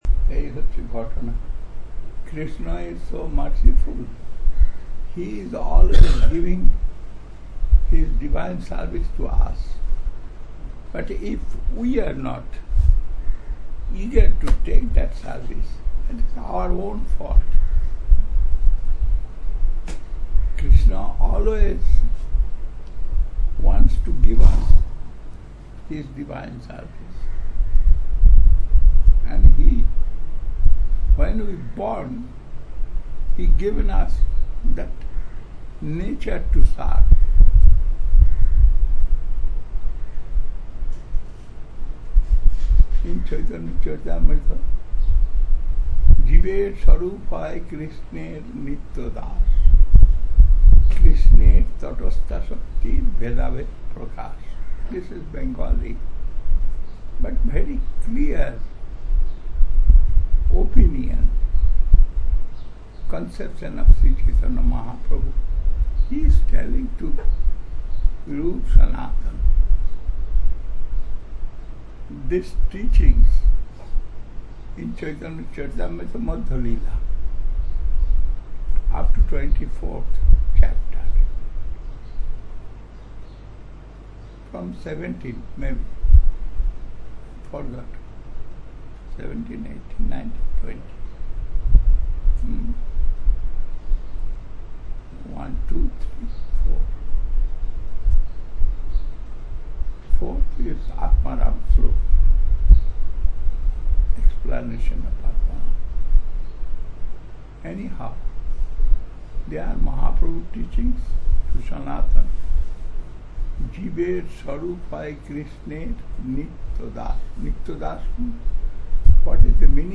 You can hear him flipping photo pages and explaining the content.)